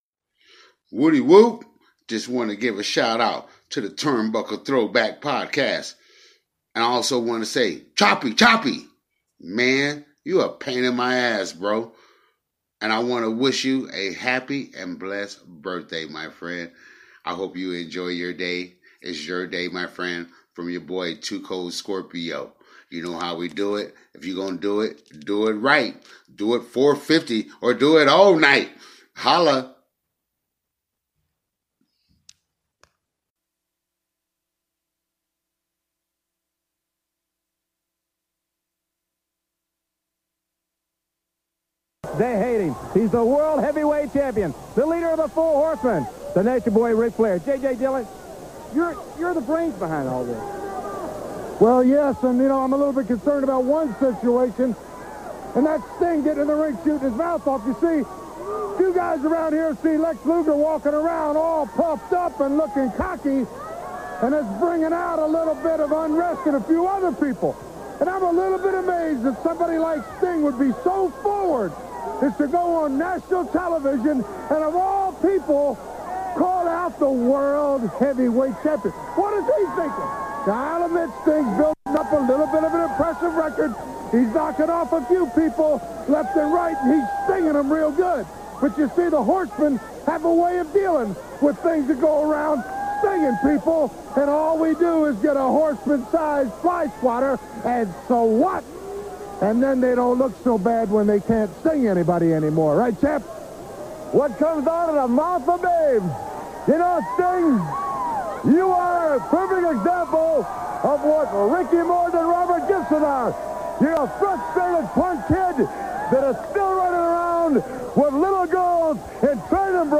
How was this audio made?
The episode falls apart when the power gets cut off in studio J before the break.